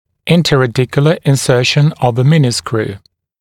[ˌɪntəræ’dɪkjələ ɪn’sɜːʃn əv ə ‘mɪnɪskruː][ˌинтэрэ’дикйэлэ ин’сё:шн ов э ‘минискру:]установка мини-винта в межконевое пространство